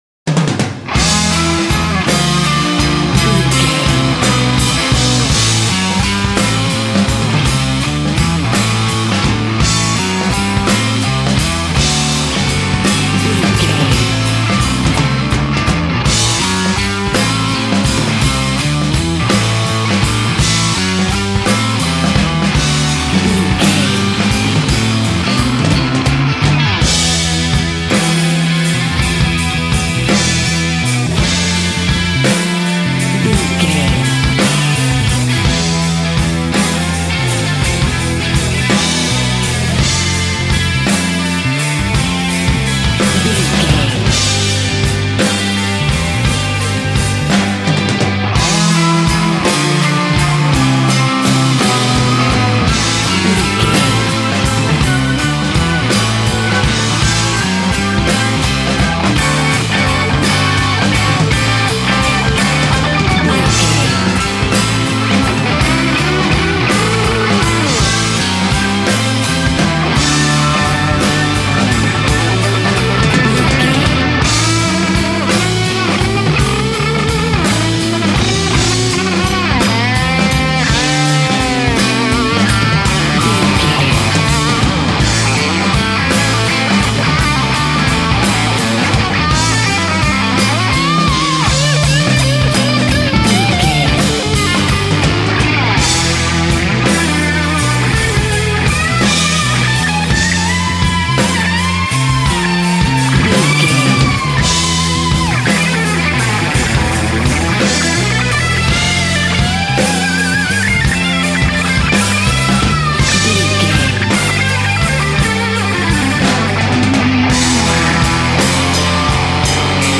Ionian/Major
electric guitar
drums
bass guitar
hard rock
aggressive
energetic
intense
nu metal
alternative metal